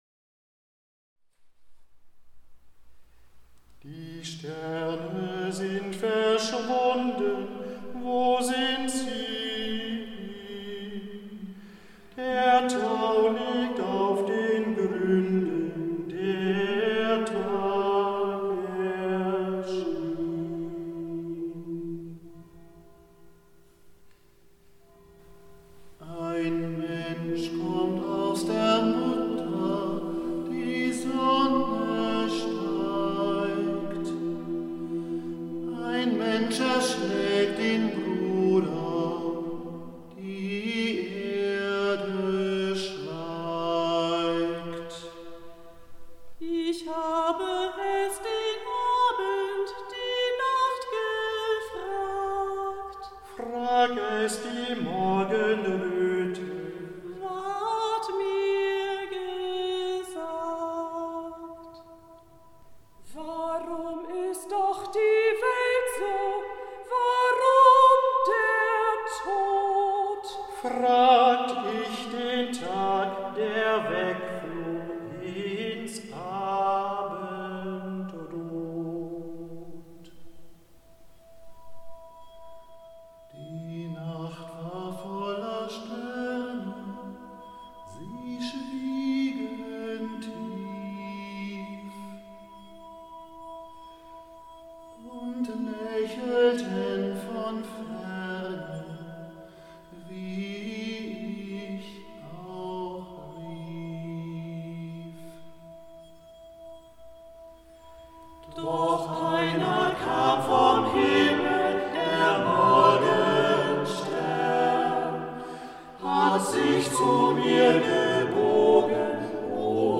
das Weihnachtslied